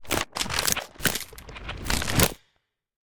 creaking_twitch.ogg